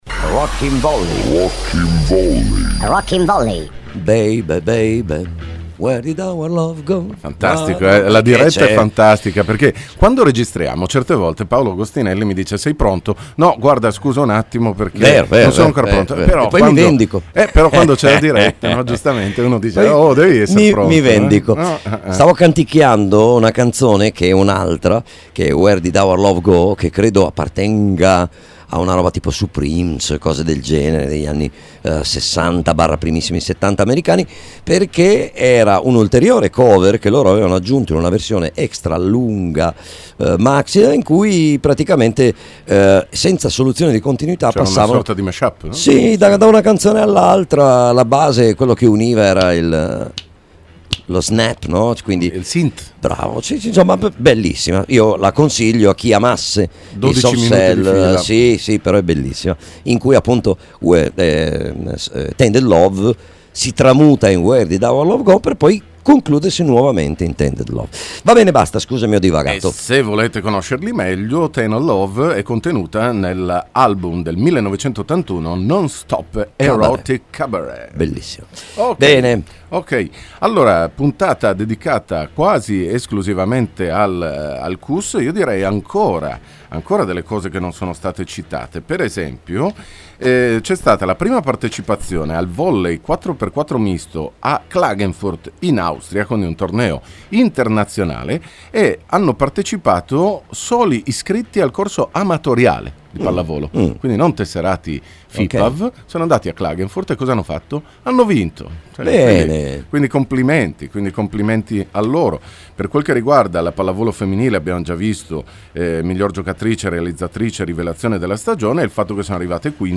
Interviste con Radio Attività